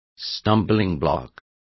Also find out how tropiezo is pronounced correctly.